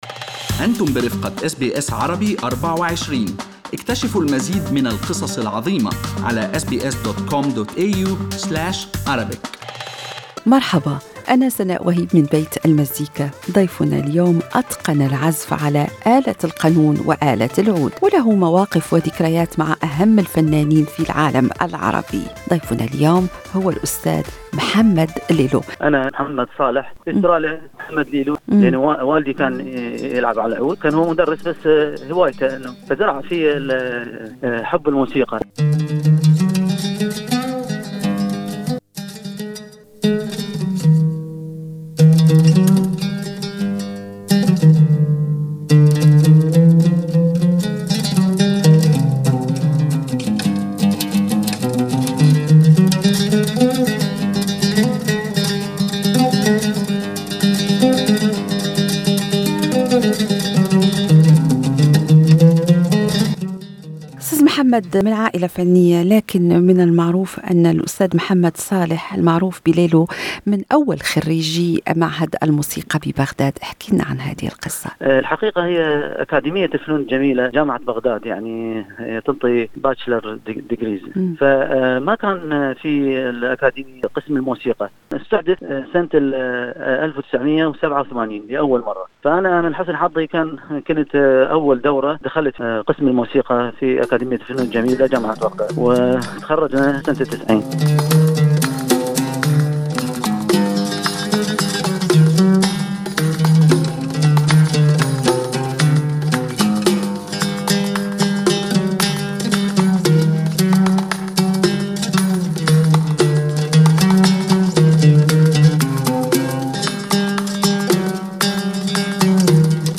يستضيف بيت المزيكا في حلقته الأسبوعية عازف القانون والعود العراقي